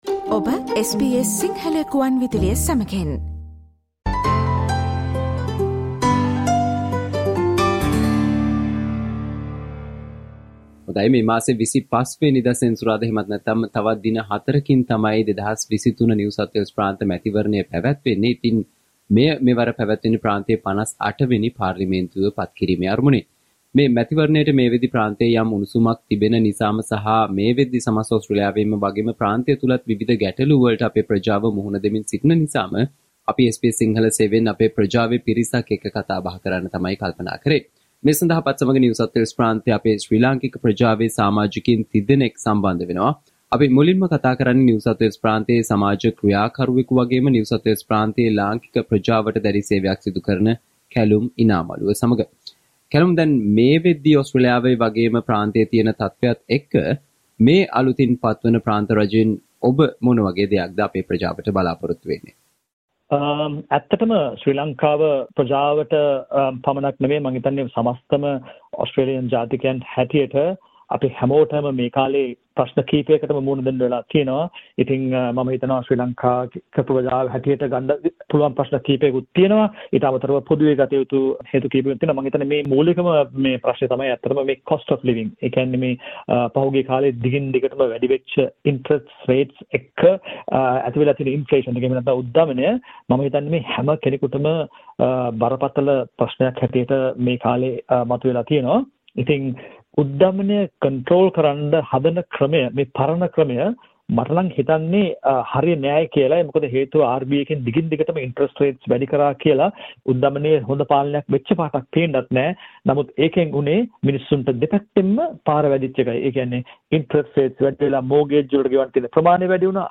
Listen to the feature produced by SBS Sinhala Radio on what Sri Lankan voters living in New South Wales expect from the new New South Wales State Government that will be elected in the New South Wales State Election on 25 March 2023.